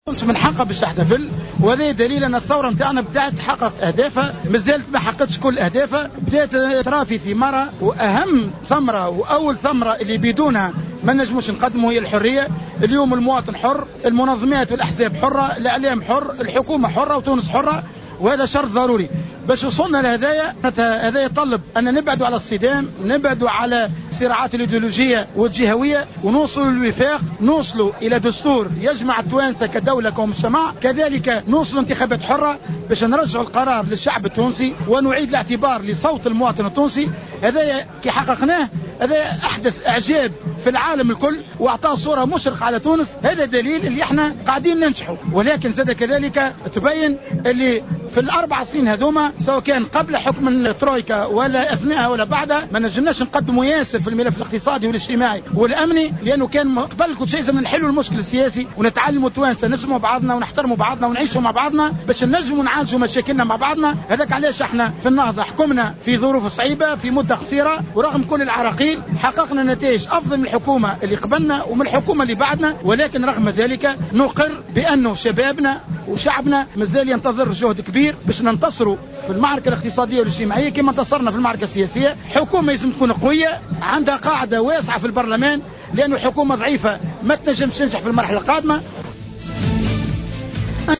Abdelkarim Harouni, leader au mouvement Ennahdha, a indiqué ce mercredi 14 janvier 2015 dans une déclaration accordée à Jawhara FM, que les célébrations de ce jour signifient que la révolution a commencé à réaliser ses objectifs, malgré l’échec des gouvernements successifs.